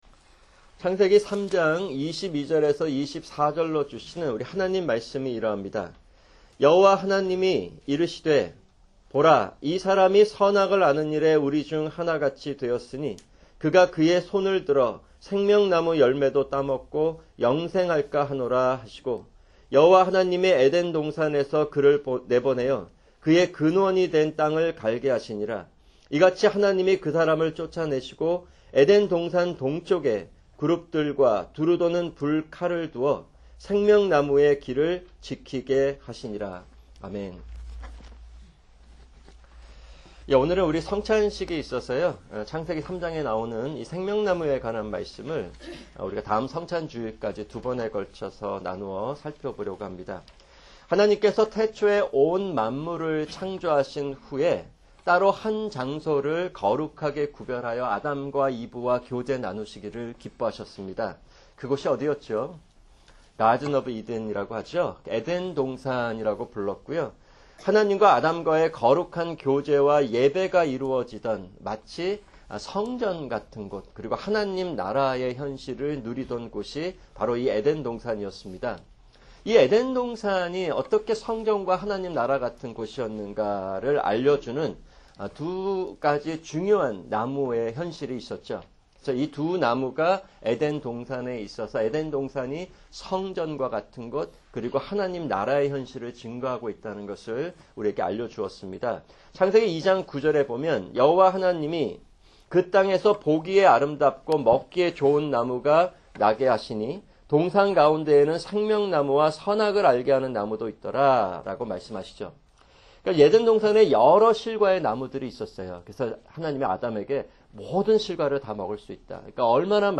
[주일 설교] 창세기 3:22-24(1)